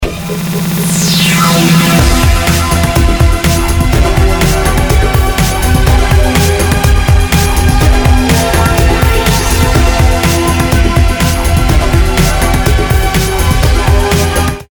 Посоветуйте библиотеки ударных